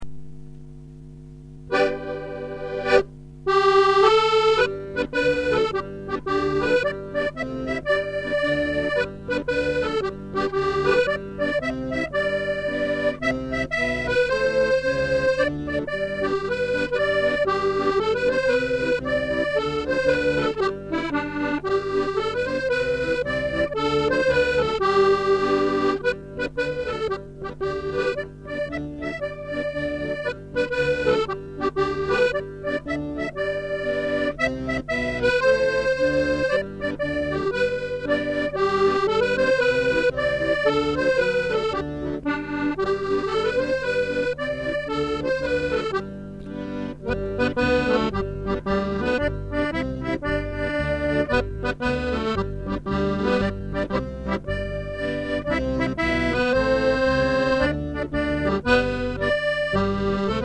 Music - set tune (this is done to a 20 bar jig. The call is usually sung)